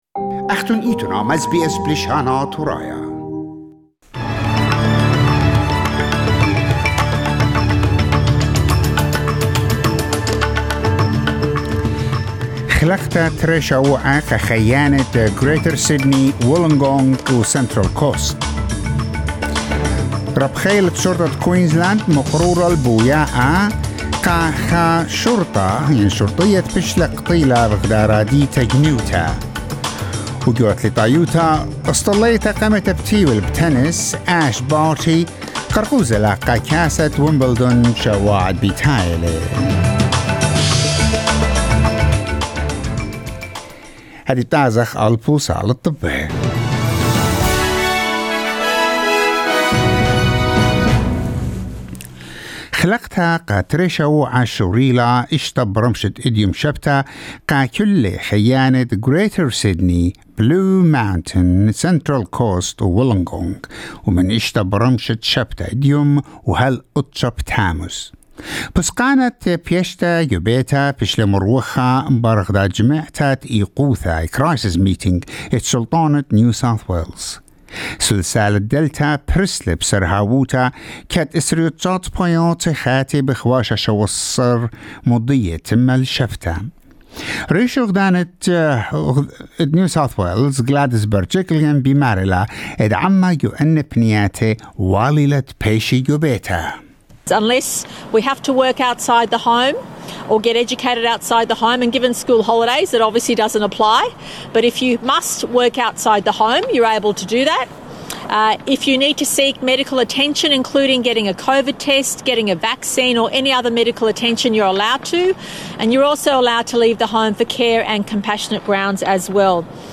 Latest SBS National and International News